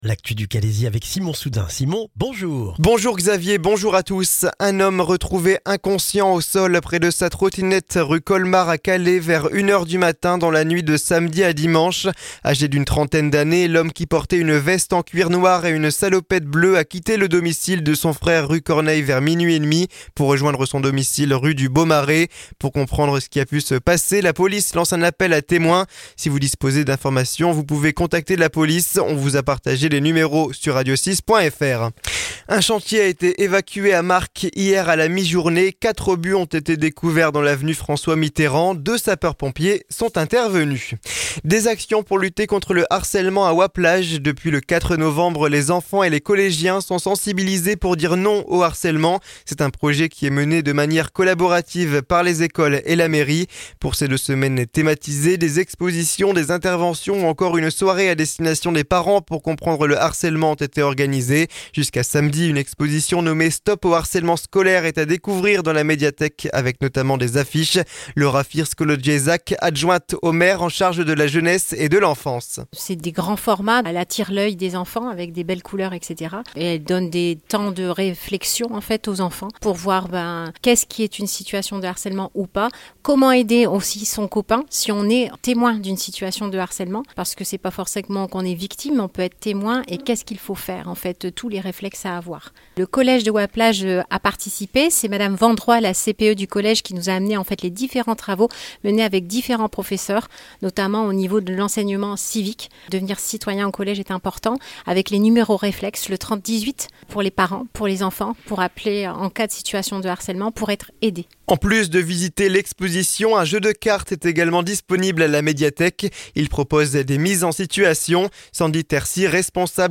Le journal du jeudi 14 novembre dans le Calaisis